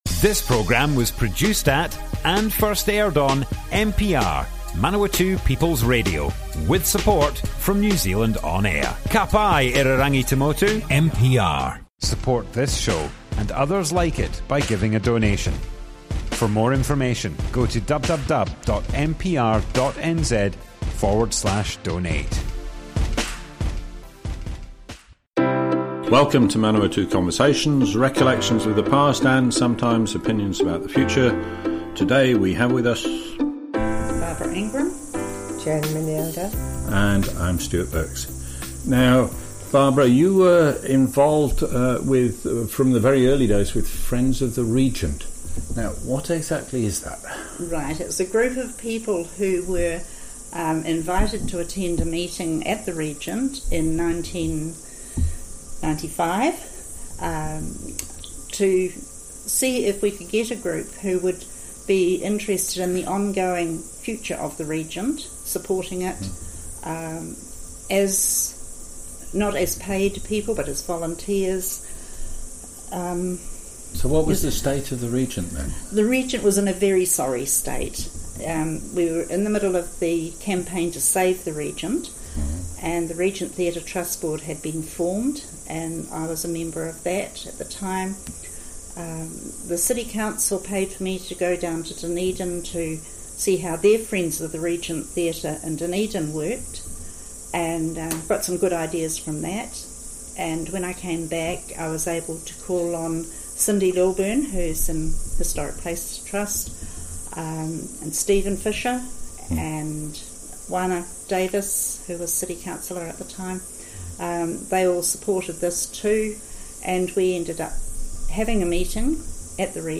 Manawatu Conversations More Info → Description Broadcast on Manawatu People's Radio, 31st March 2020. Friends of the Regent set up in 1995, volunteers to support the renovation and running of the Regent Theatre.
oral history